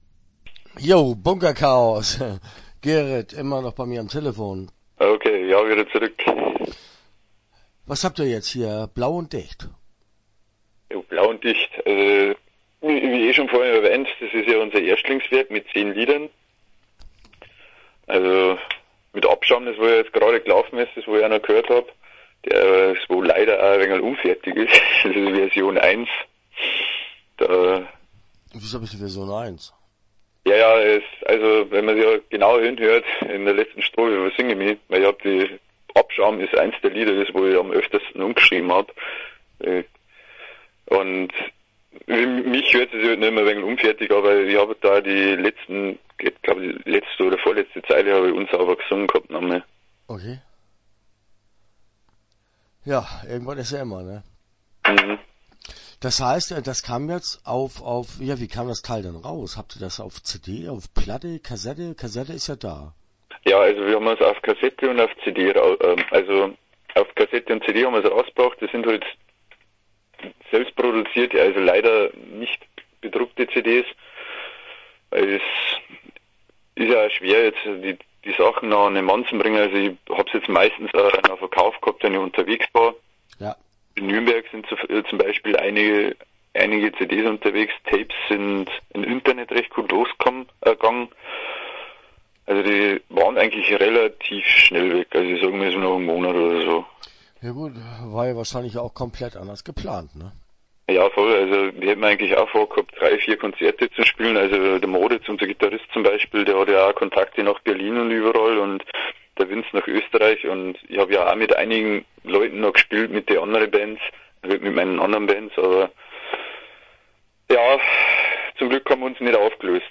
Start » Interviews » Bunker Chaos